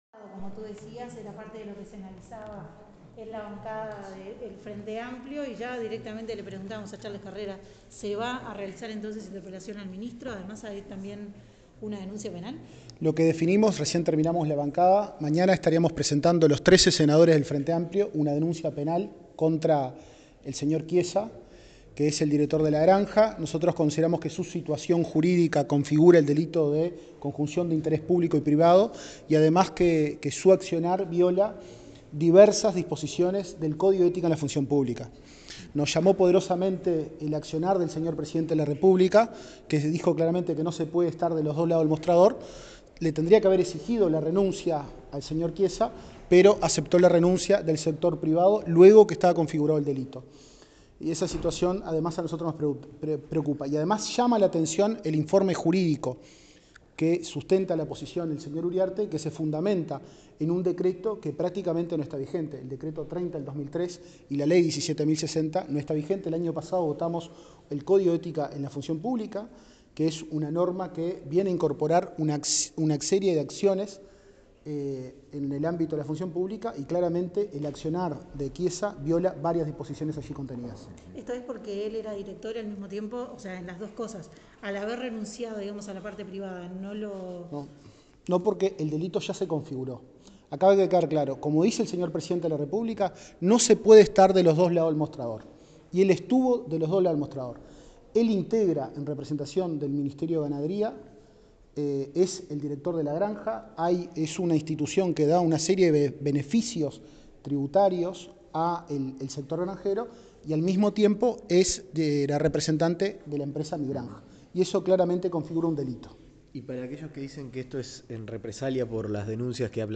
La bancada de senadores y senadoras frenteamplistas decidió presentar una denuncia penal contra el Sr. Chiesa, director de La Granja, en tanto se considera que su situación jurídica configura el delito de Conjunción de Interés Público y Privado y que además su accionar viola diversas disposiciones del Código de Ética de la Función Pública, explicó el senador Charles Carrera en declaraciones a la prensa.